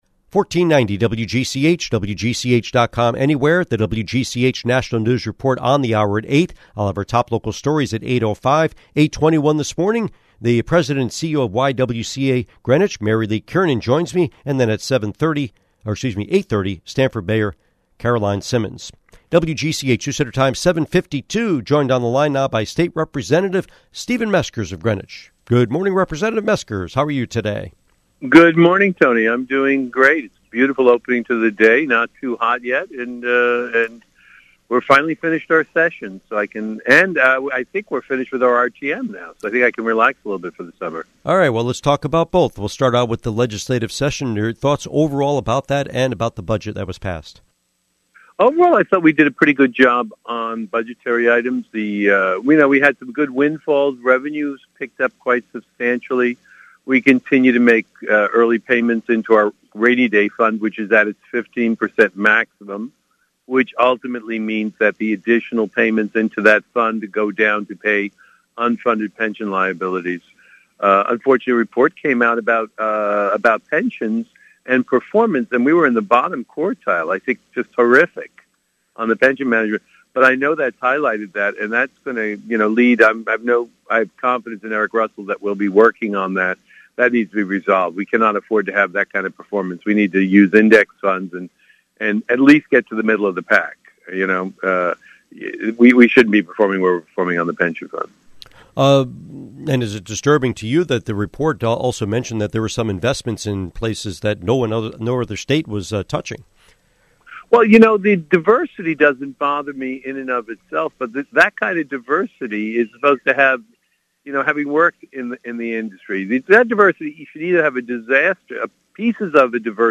Interview with State Representative Meskers